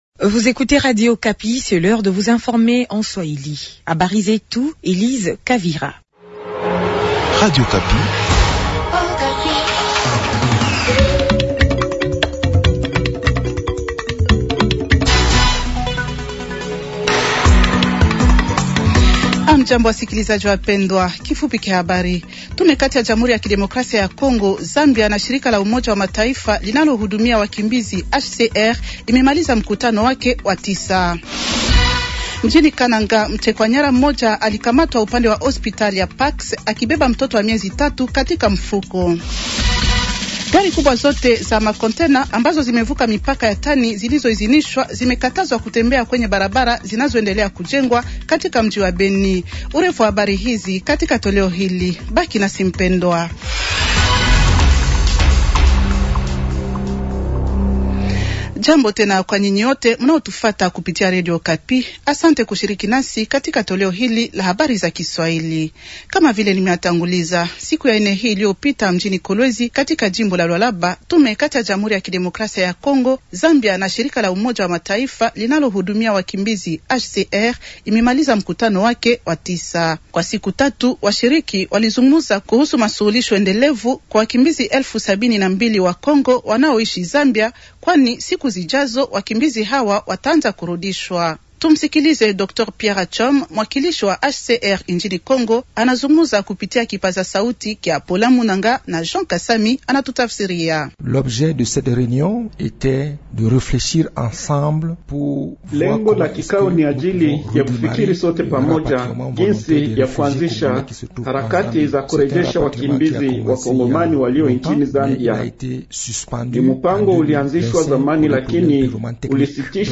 Journal swahili de vendredi soir 130326